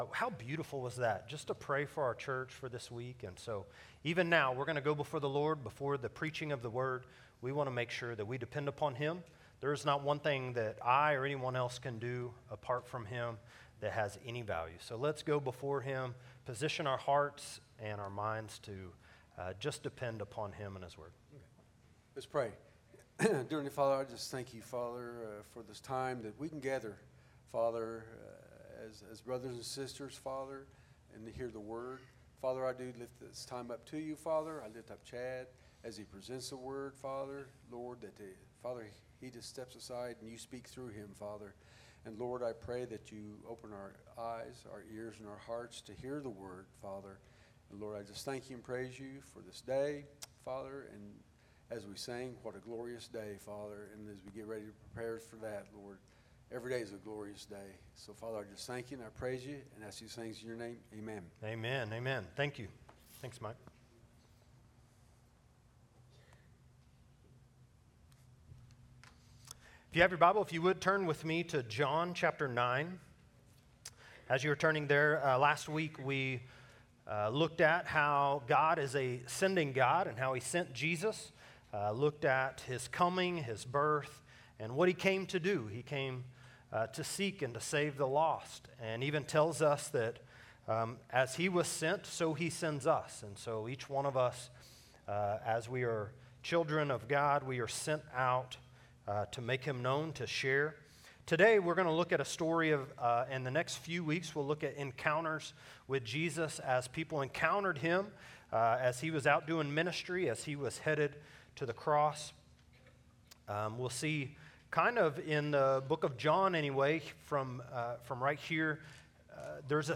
A message from the series "Encounters with Jesus." Title: Hope of the Resurrection Scripture: John 20: 1-9 Series: Encounters with Jesus